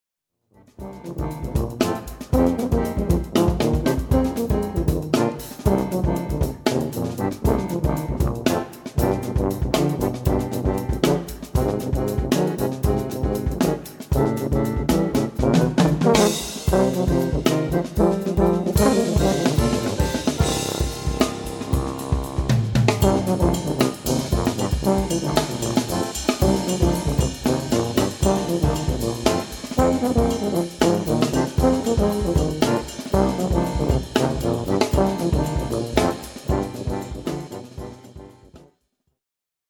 Trombone | Tuba | Bass Trombone | Euphonium | Sacqueboute